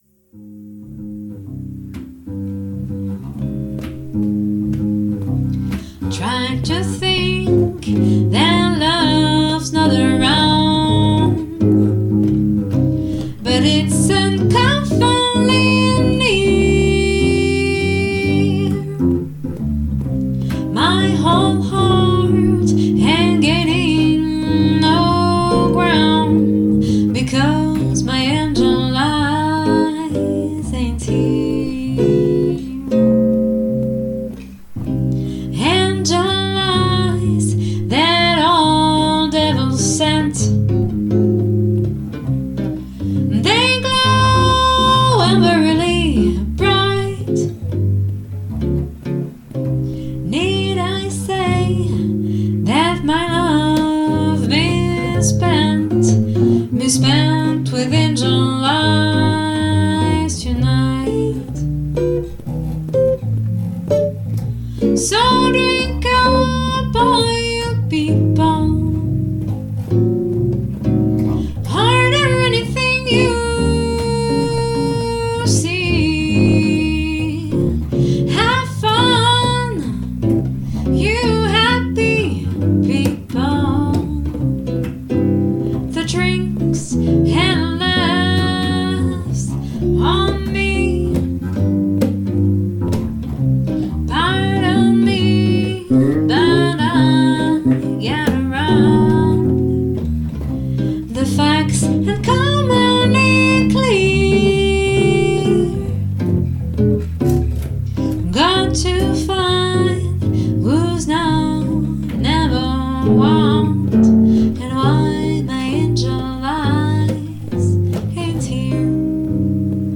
avant hier répet sans le batteur. 2 repets par semaine depuis plusieurs mois (1 jazz, 1 coverband) ça forge...